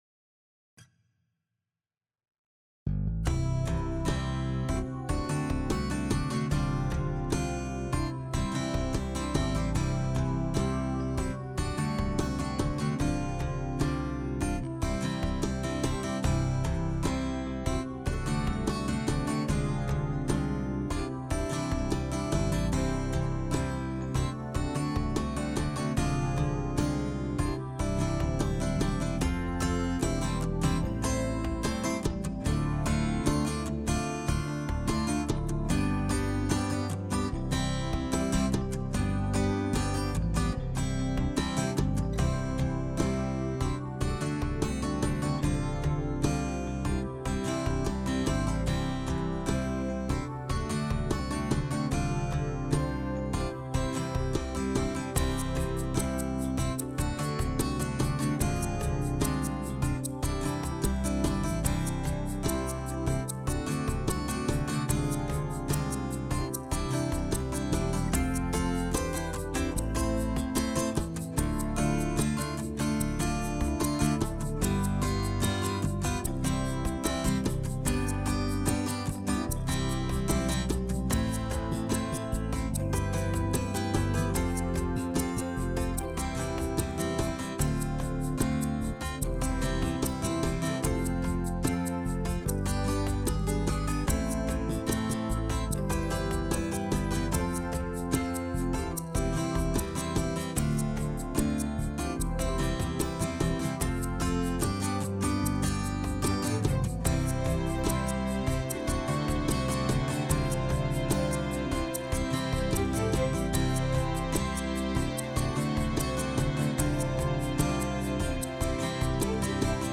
Loop Underscore